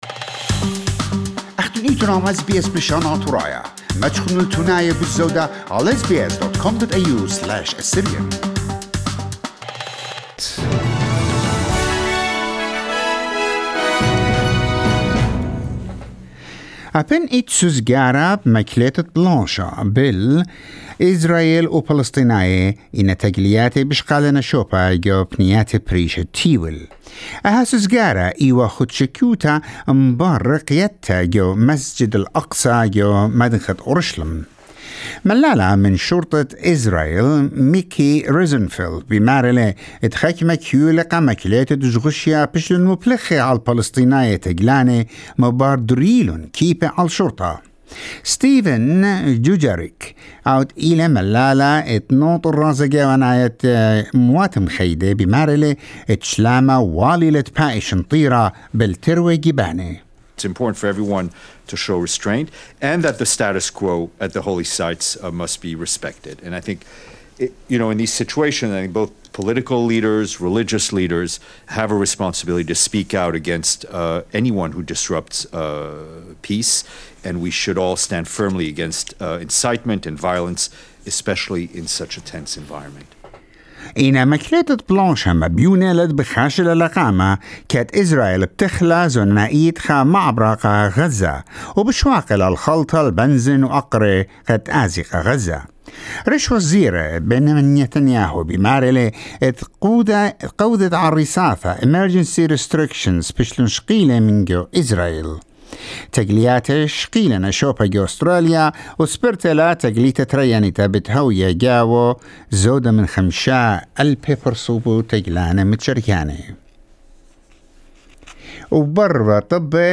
SBS News in Assyrian Saturday 22 May 2021